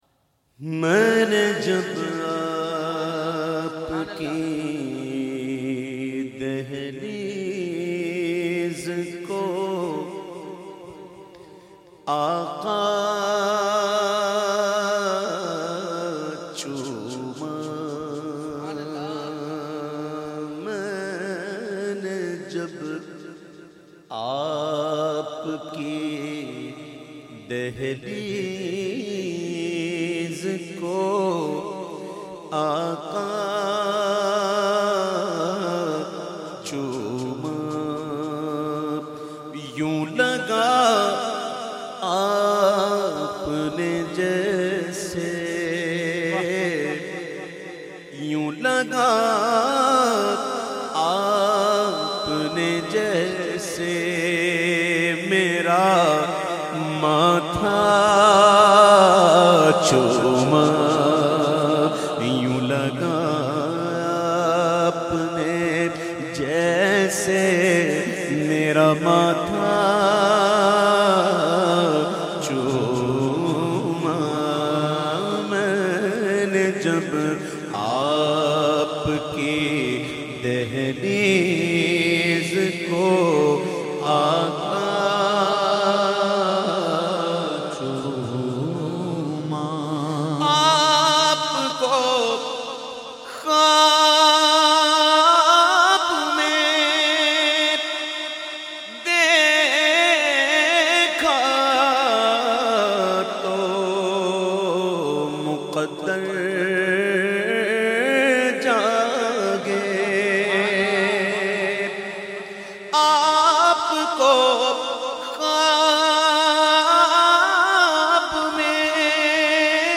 The Naat Sharif Main Ne Jab Apki Dehleez Ko recited by famous Naat Khawan of Pakistan owaise qadri.